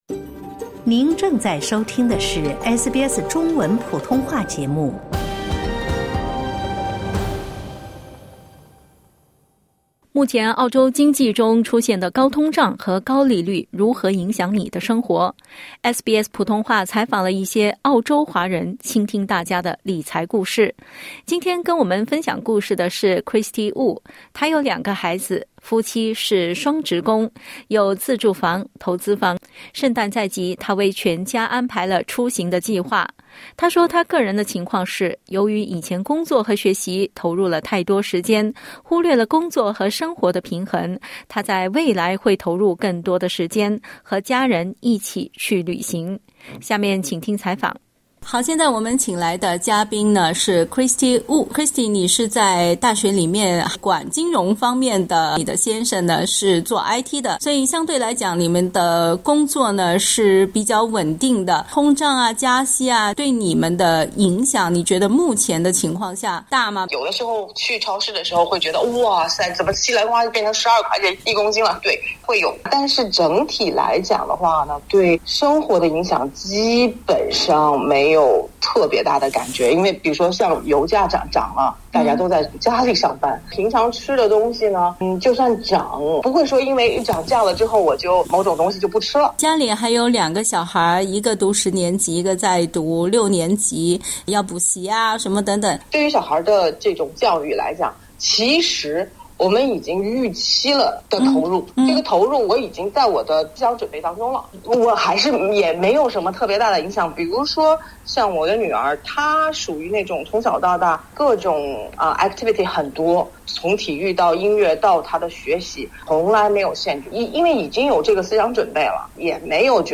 SBS普通话记者采访了一些澳洲华人，请他们分享自己的理财故事。